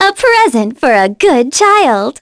Cassandra-Vox_Skill3.wav